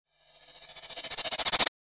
钹采样声反转
描述：只是在Logic Pro把钹的采样声反转
Tag: 过渡 反向